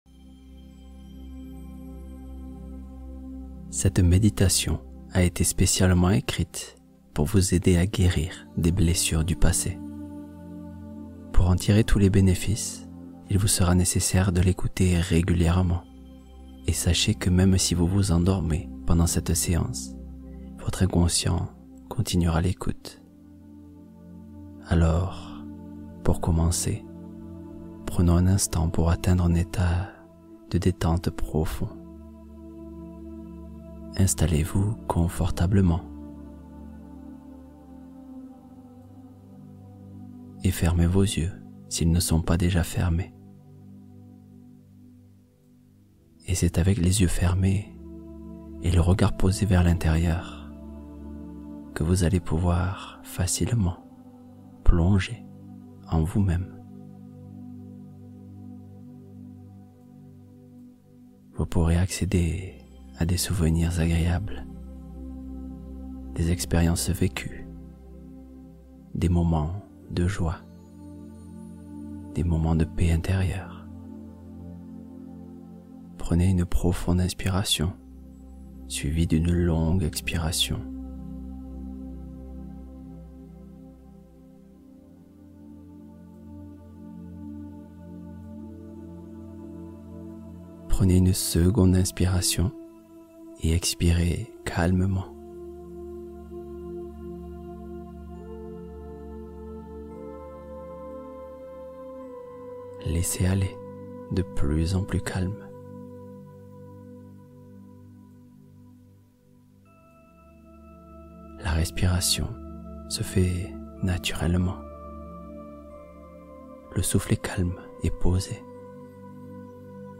Sommeil profond stabilisé — Repos guidé pour une nuit complète